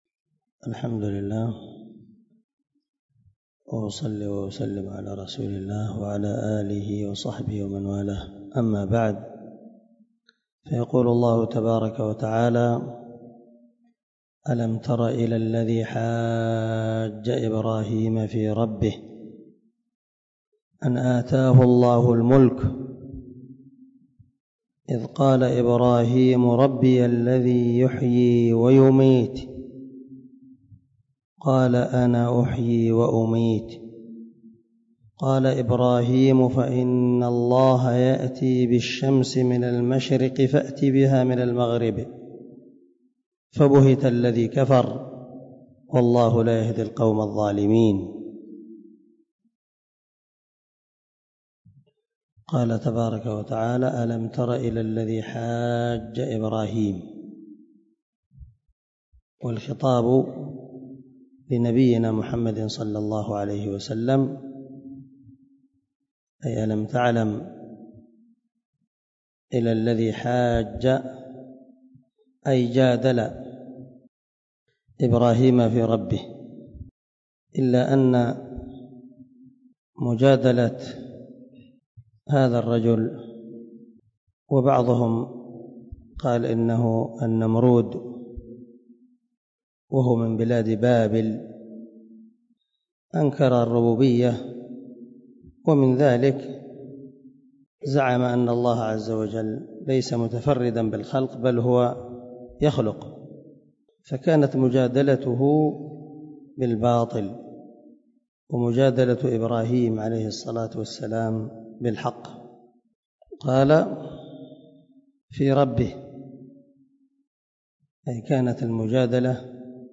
137الدرس 127 تفسير آية ( 258 ) من سورة البقرة من تفسير القران الكريم مع قراءة لتفسير السعدي